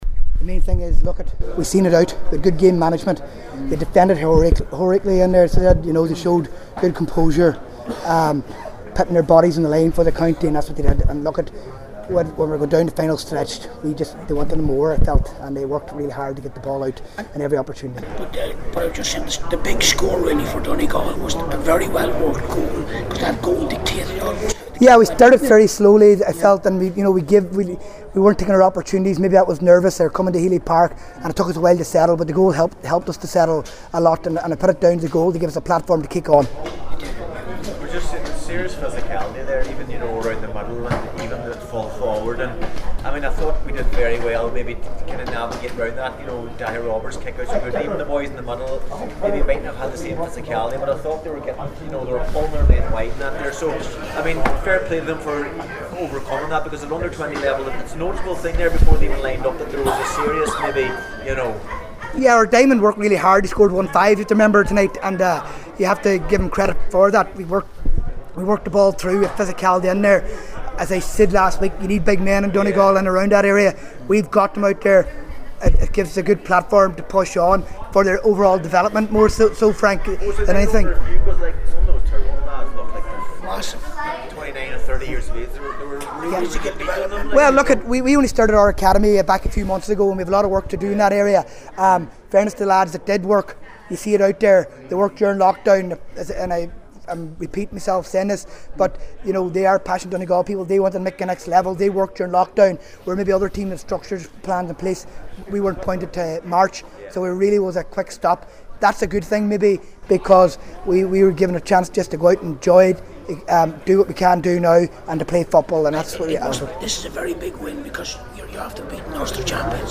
spoke with the media after the game…